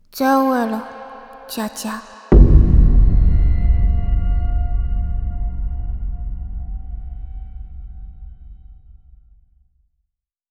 游戏失败_FX.wav